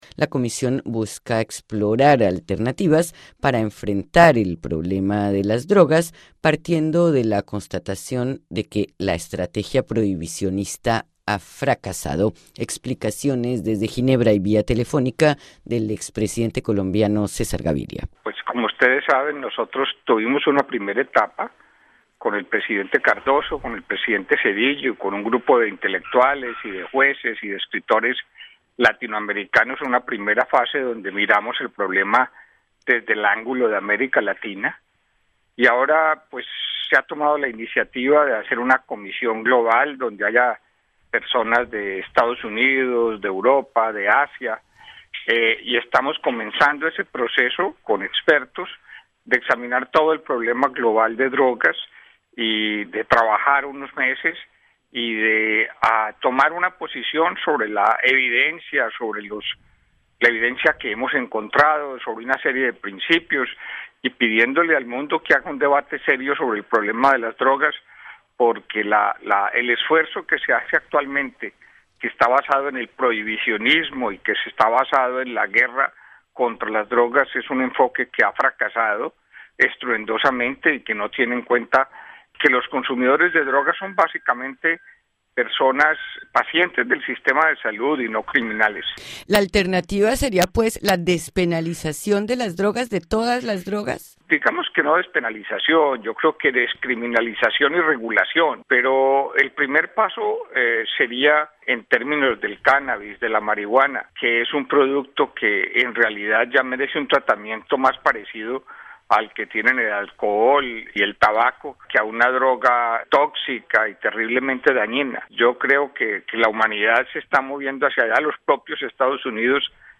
Para enfrentar el problema, se instaló en la sede de la ONU, en Ginebra, la Comisión Global sobre Drogas. Escuche el informe de Radio Francia Internacional.